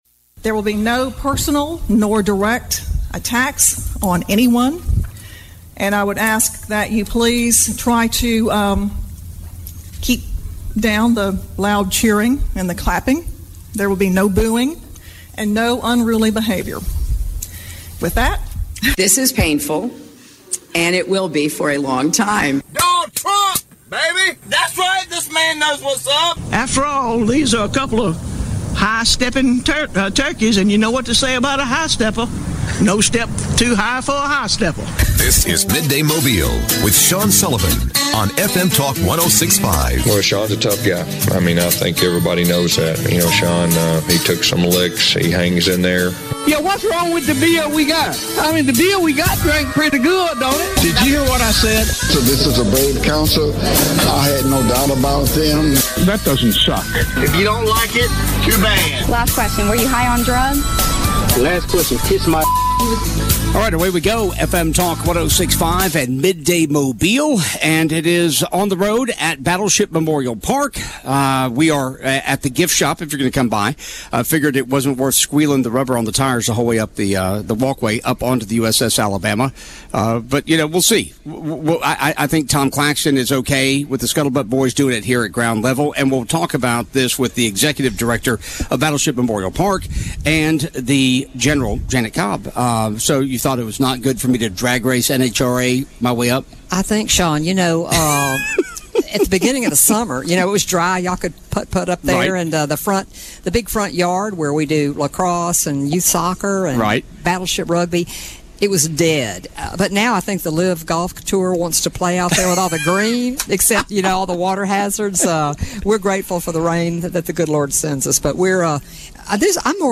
Live from Battleship Memorial Park